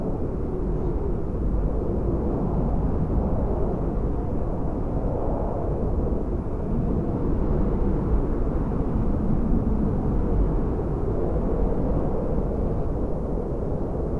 Switched out wind sfx
wind.ogg